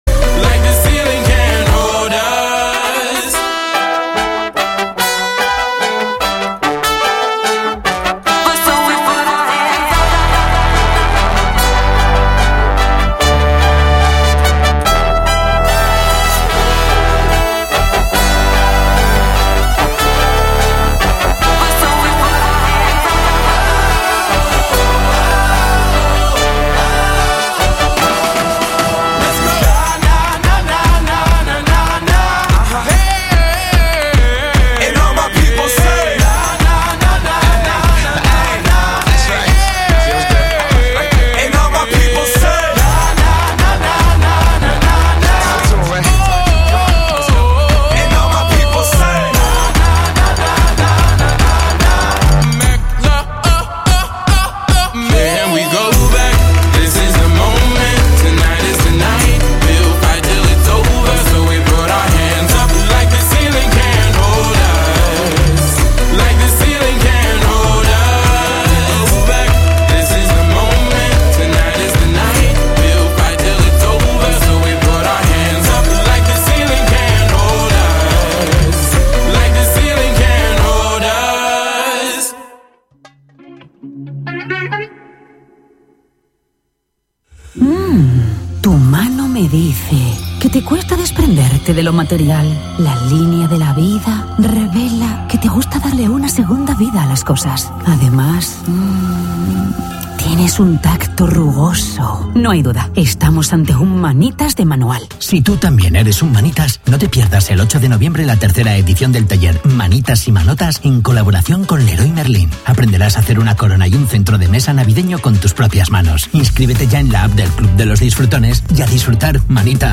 Entrevista de Ràdio: Els Jóvens i les seues Crisis Existencials després de la Universitat